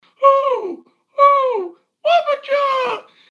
Play, download and share WHAPPPPPCHA quiet original sound button!!!!
whappacha-quiet_p62luFR.mp3